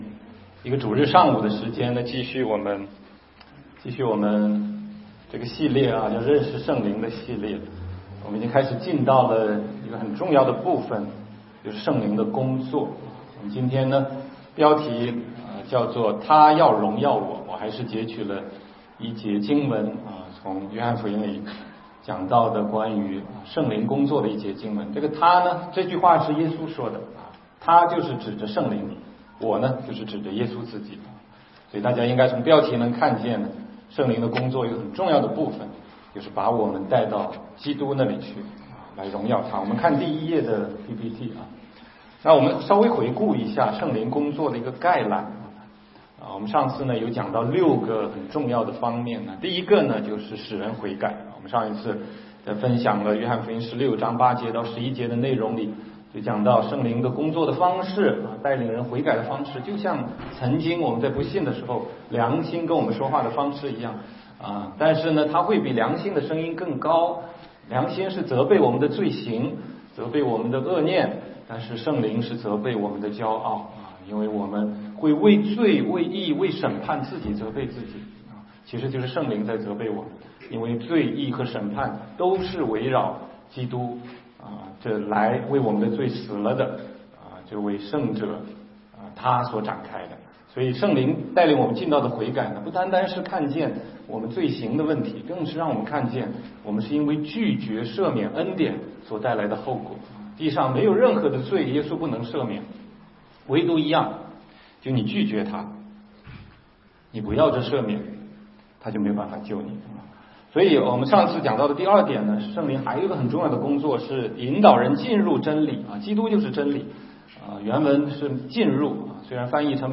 16街讲道录音 - 认识圣灵系列之四：祂要荣耀我